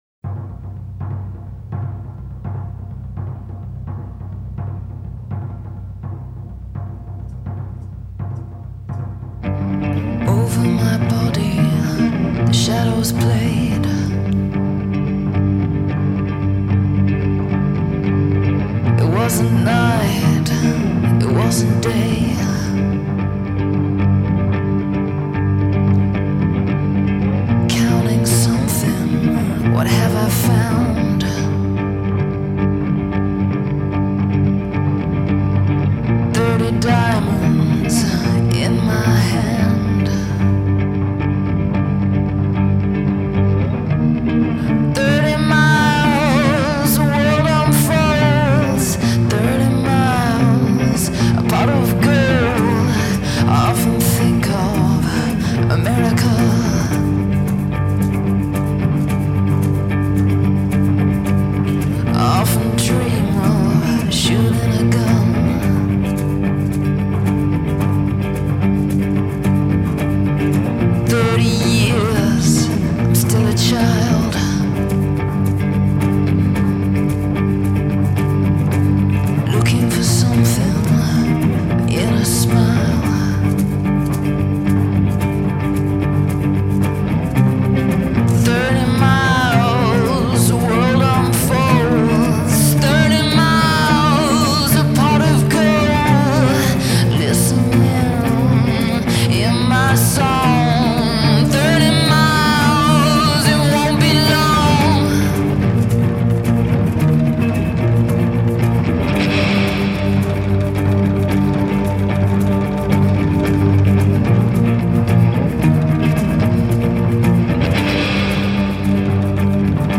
live radio session versions